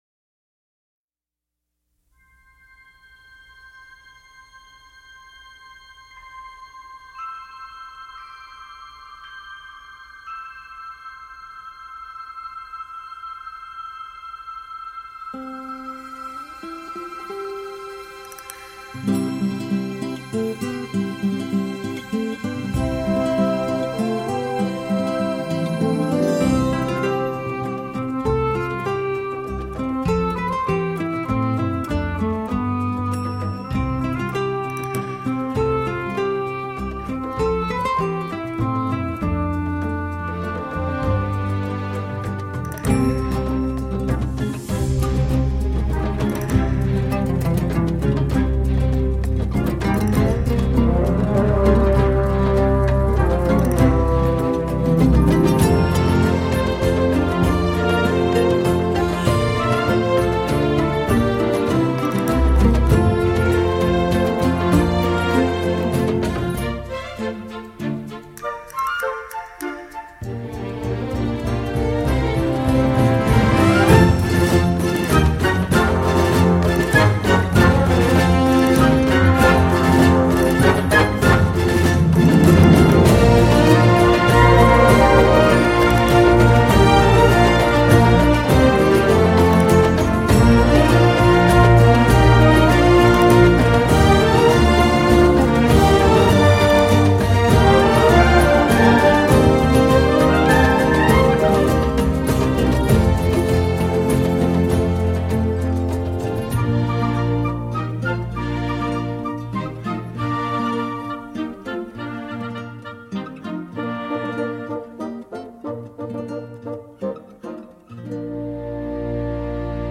gimmicks à la guitare et de poncifs en clochettes